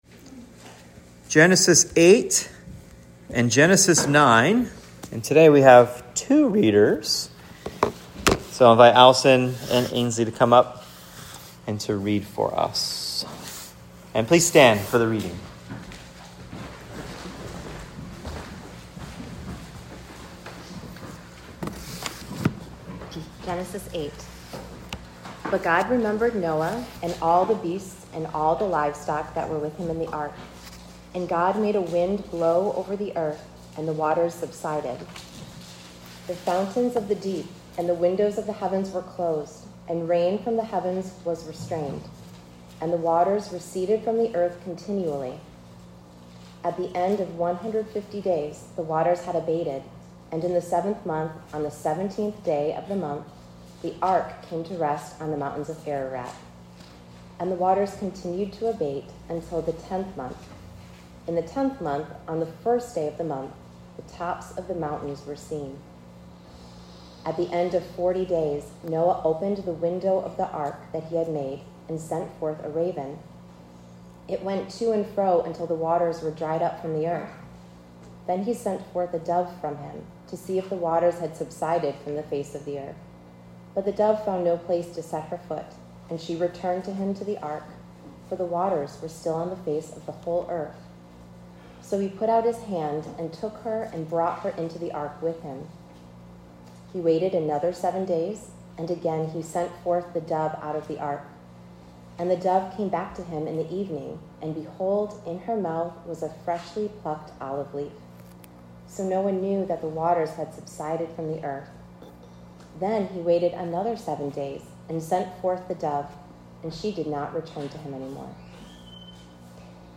Genesis 8-9 Sermon “The Flood” Part 2